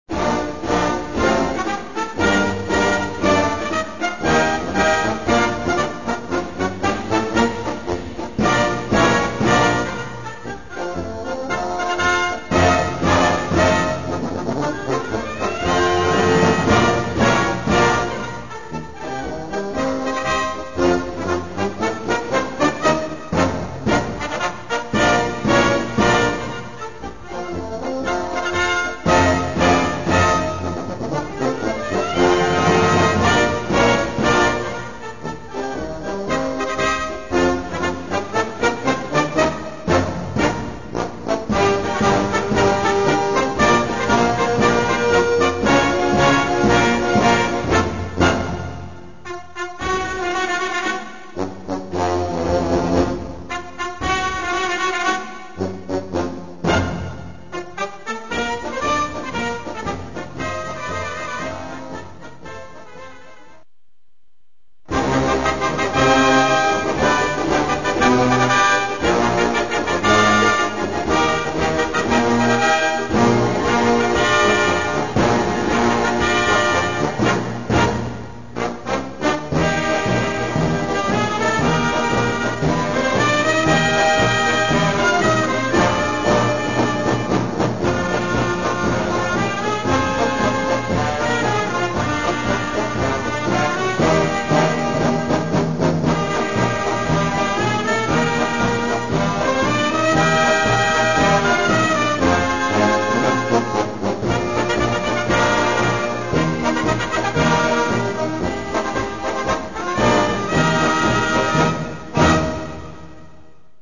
Gattung: Bravour-Marsch
Besetzung: Blasorchester